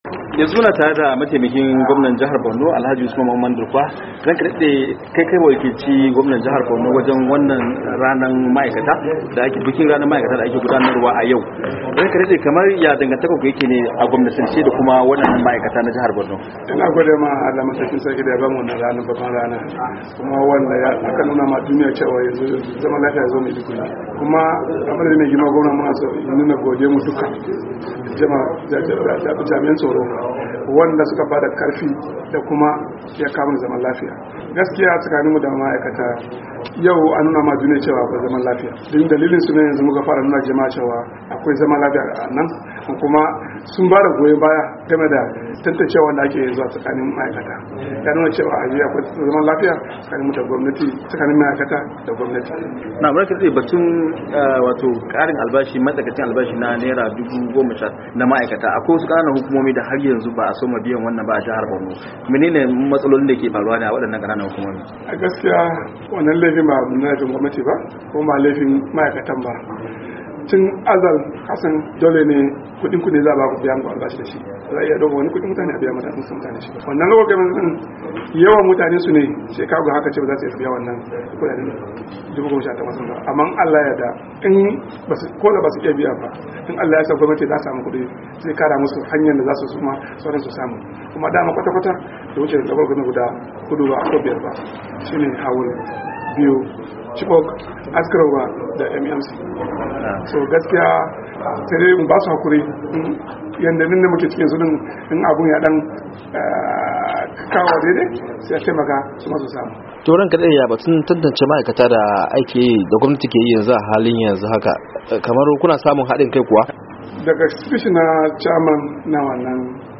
Mataimakin gwamnan jihar Borno Alhaji Usman Mamman Durkwa wanda ya wakilci gwamna Kashim Shettima a bikin ranar ma'aikata da aka yi jiya , ya zanta da Muryar Amurka inda ya bayyana irin fahimtar juna dake tsakanin gwamnati da ma'aikatan jihar.